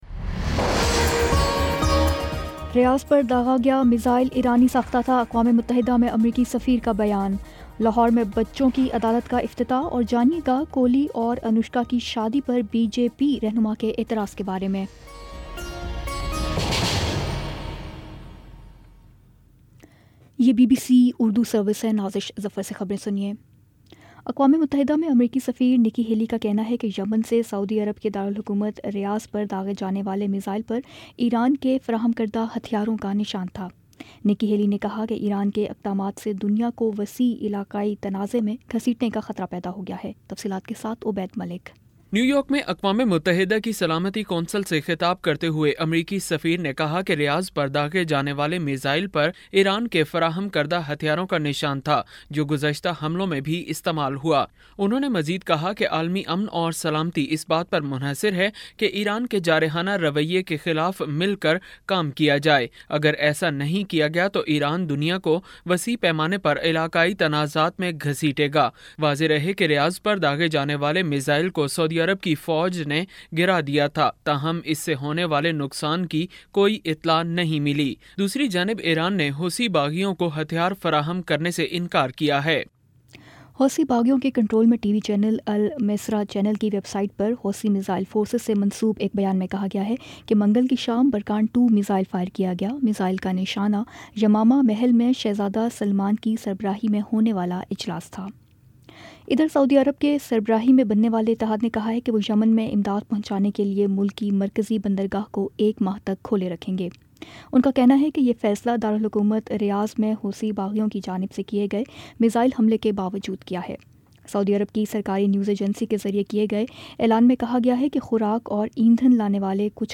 دسمبر 20 : شام پانچ بجے کا نیوز بُلیٹن